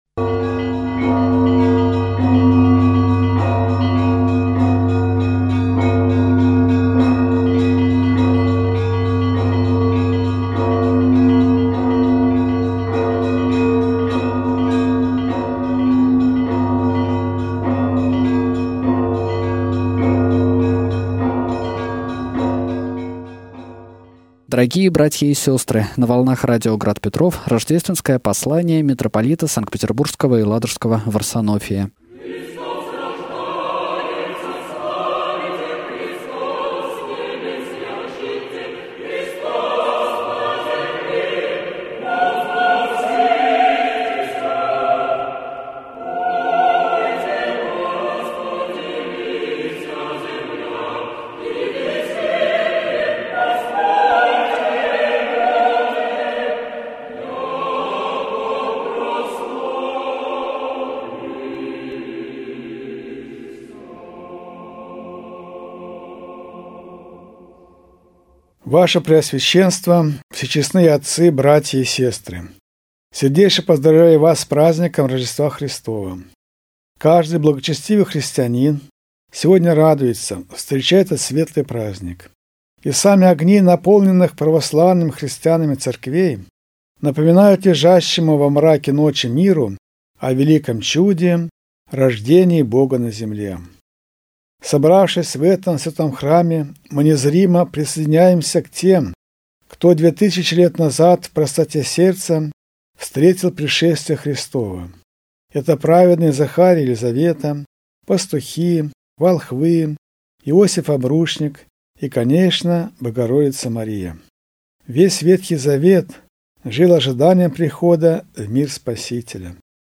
РОЖДЕСТВЕНСКОЕ ПОСЛАНИЕ
Rozhdestvenskoe-poslanie-mitropolita-studijnaya-zapis.mp3